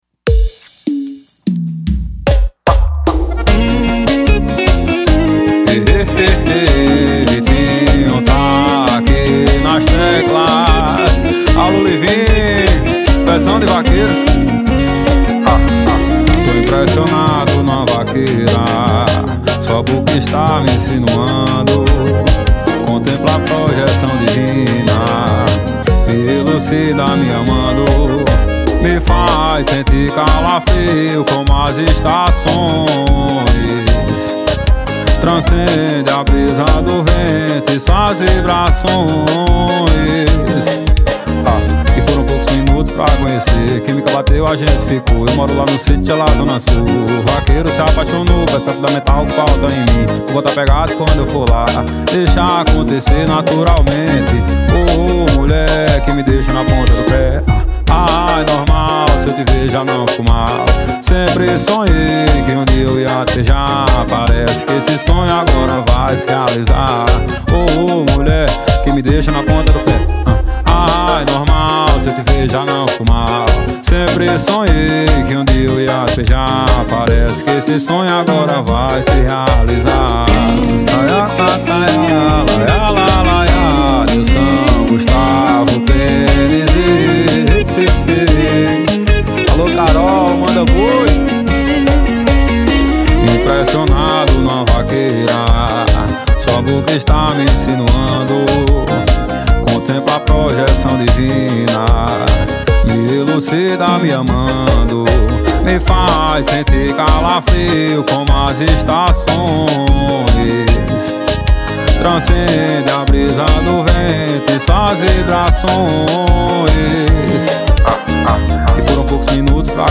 2024-02-14 17:56:55 Gênero: Forró Views